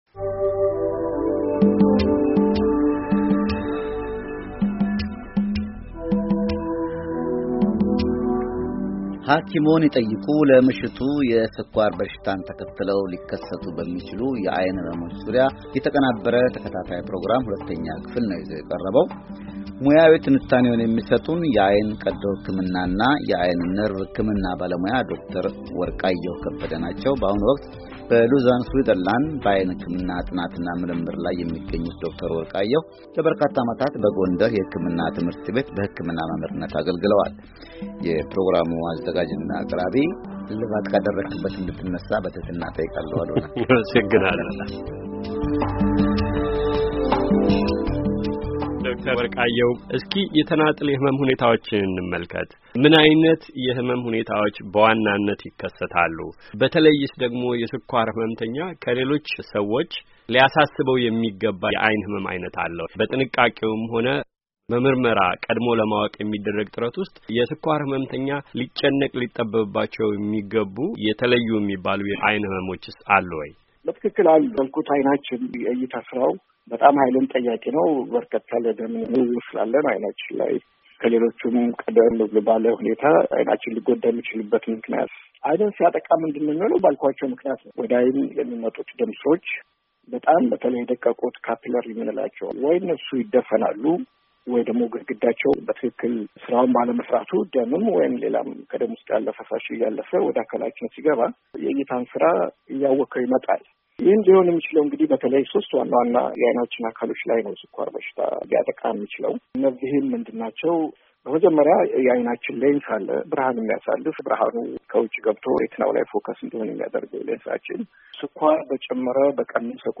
ሞያዊ ትንታኔውን የሚሰጡን የዓይን ቀዶ ሕክምናና የዓይን ነርቭ ሕክምና ባለሞያው